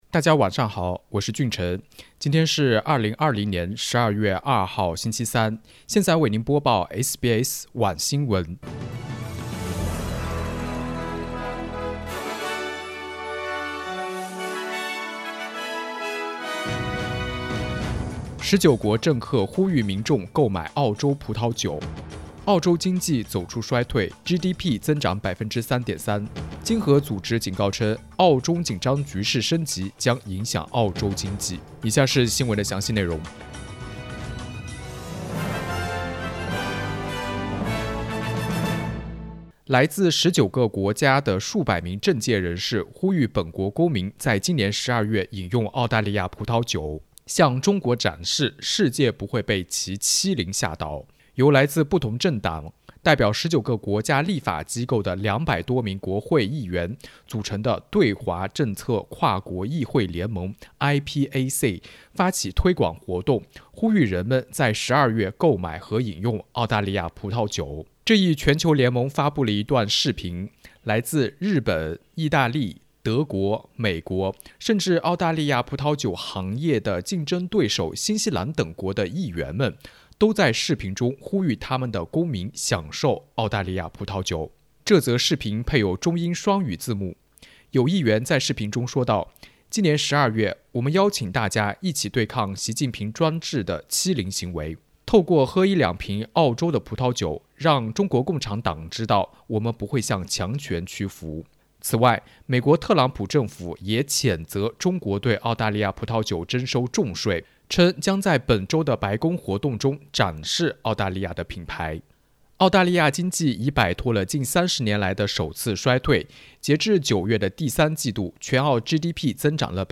SBS Mandarin evening news Source: Getty Images
1202_mandarin_evening_news.mp3